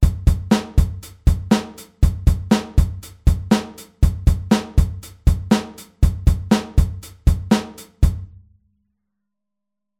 Als Veränderung zur vorherigen Variante nehmen wir den rechten Fuß auf dem UND nach der Zählzeit 2 einfach ( 😉 ) wieder dazu (also eine Mischung aus Variante 3 und 4):
8telBeats06.mp3